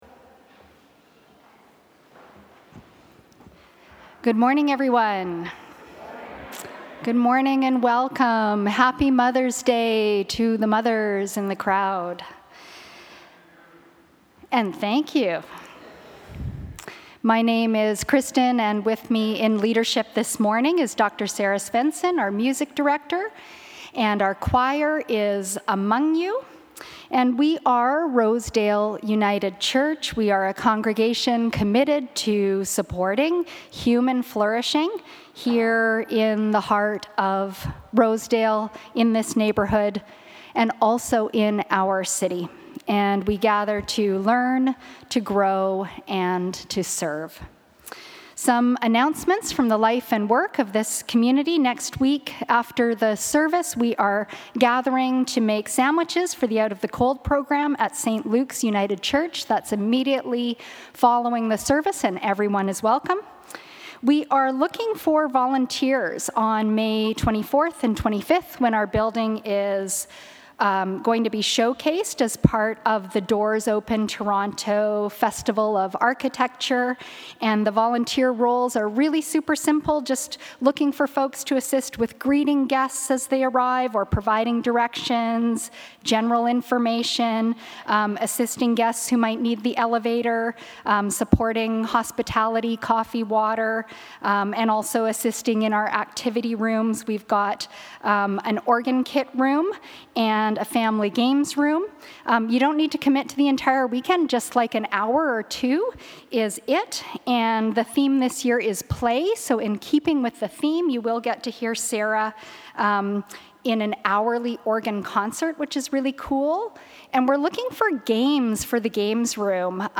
Rosedale United Services | Rosedale United Church